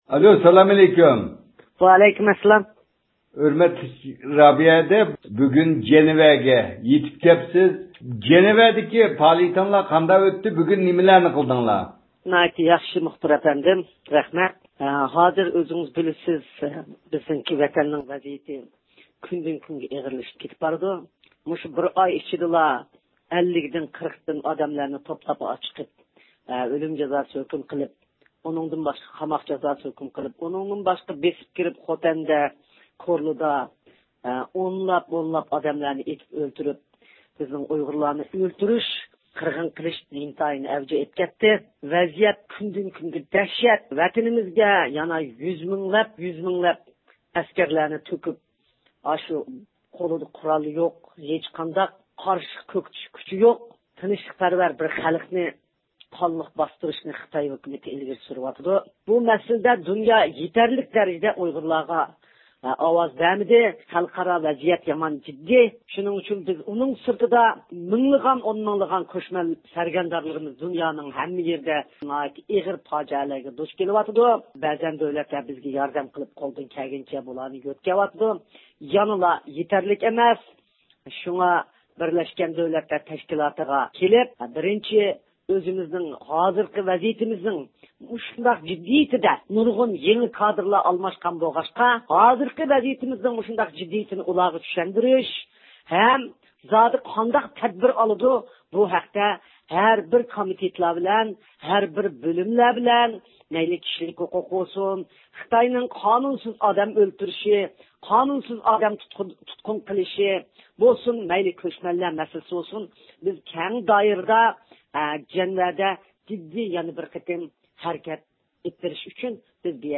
بۈگۈنكى پائالىيەت توغرىسىدا رابىيە خانىم بىلەن سۆھبەت ئېلىپ باردۇق.